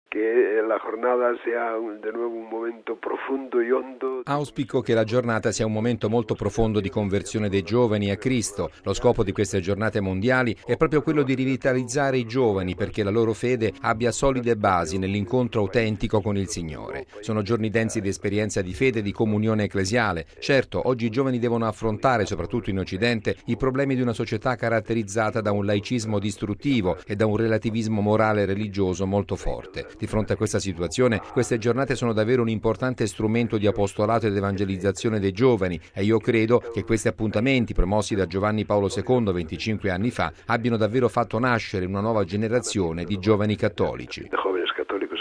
cardinale Antonio María Rouco Varela, arcivescovo di Madrid